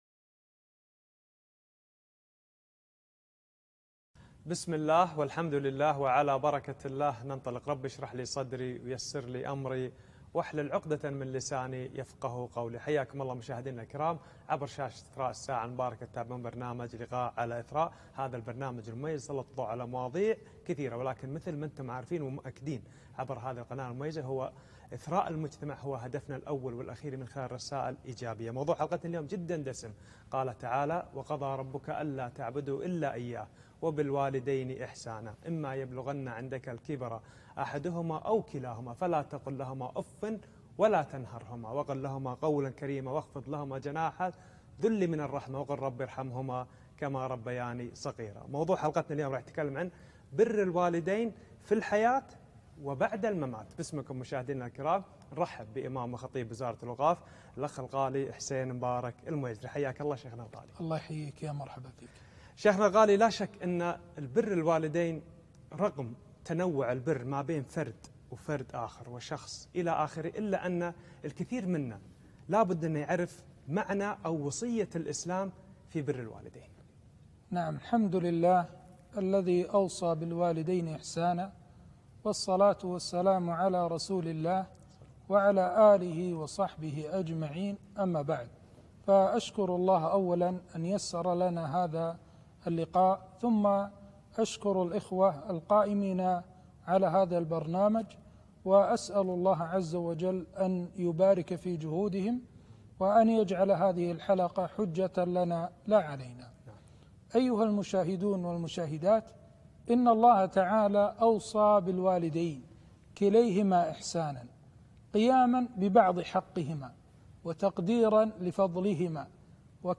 لقاء تلفزيوني- برالوالدين في الحياة وبعد الممات